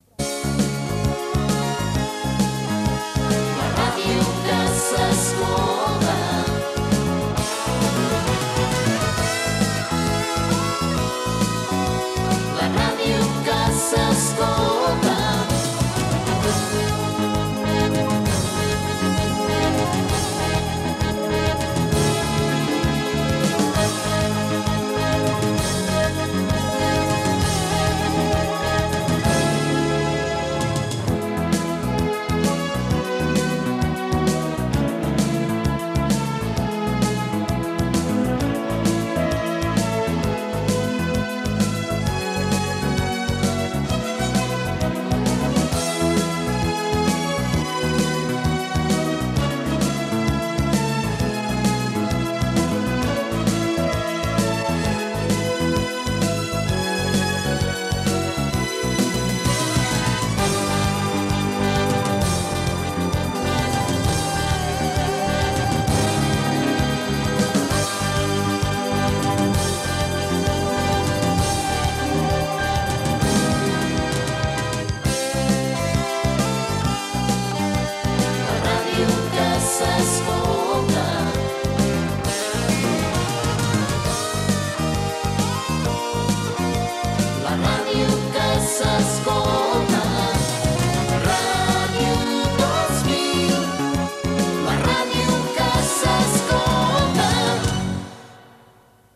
Jingle instrumental i cantat